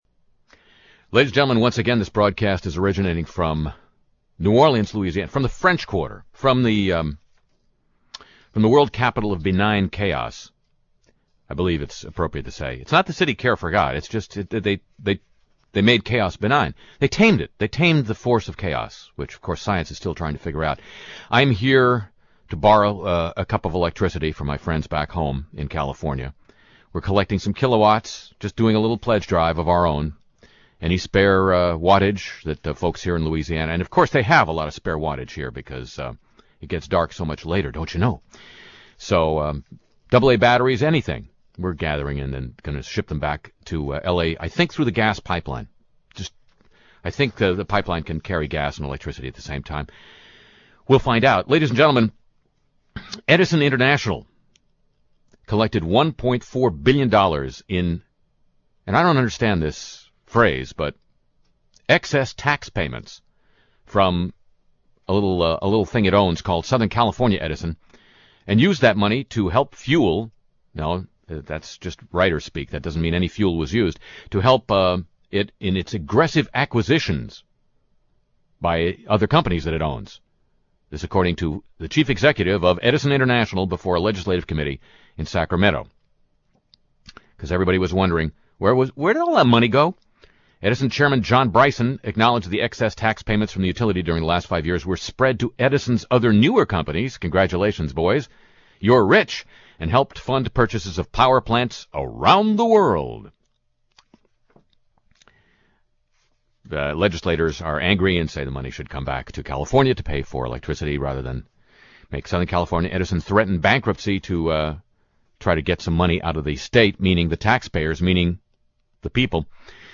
Dick TV's Sports Crib: Sports Talk with Dick Vitale sketch